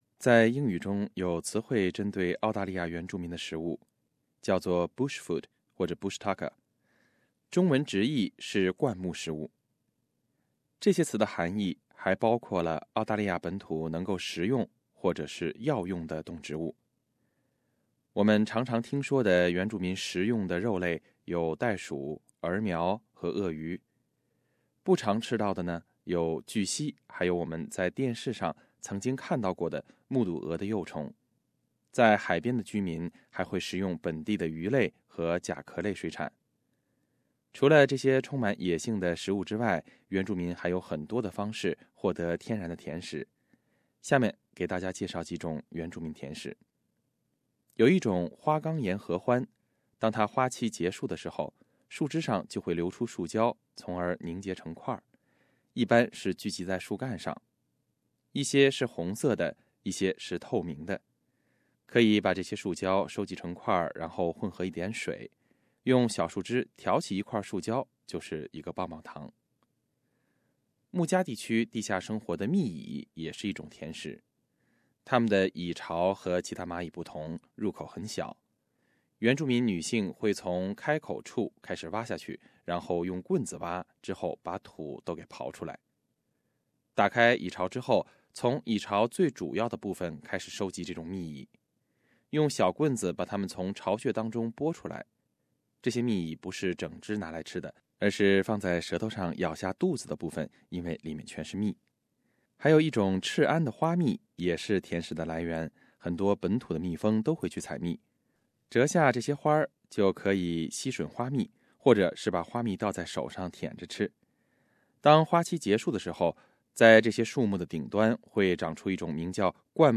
原住民有哪些独特的甜食？又对食物有什么特殊要求？ 请点击收听报道。